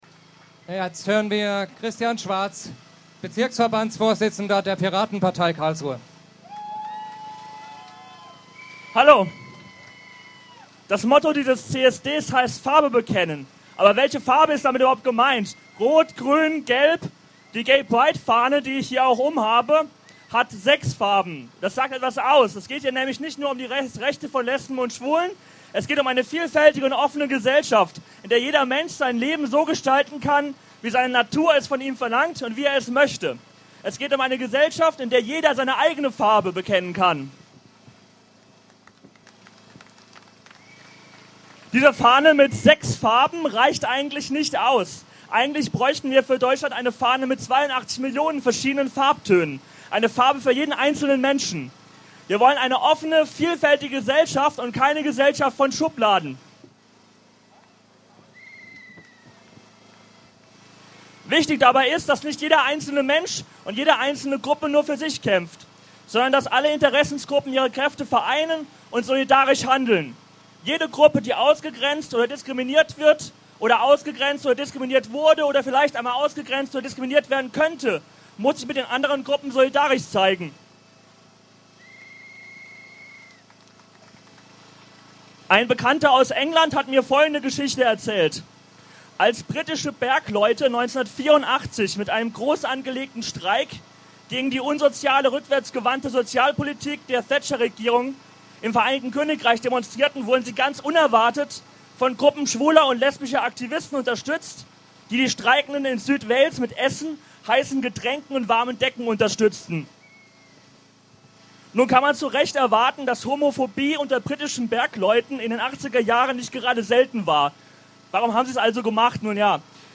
04. Juni 2011, Friedrichsplatz (Kundgebung)